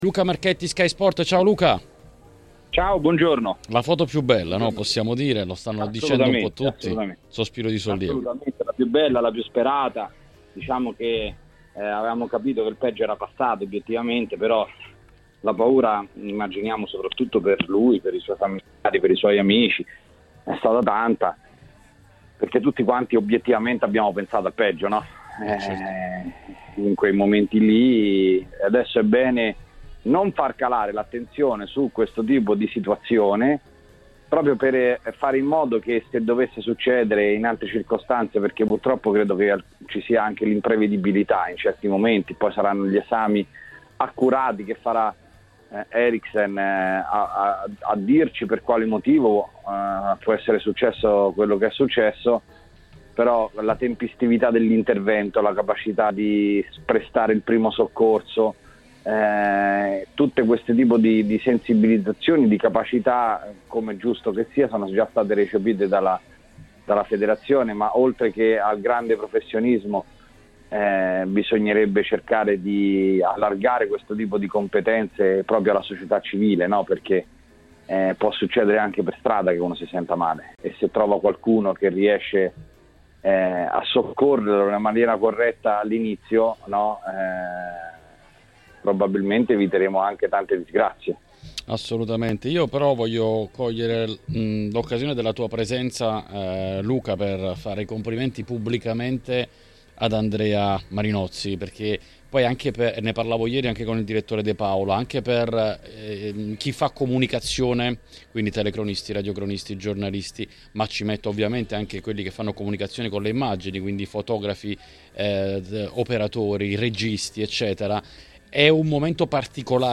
in diretta su TMW Radio: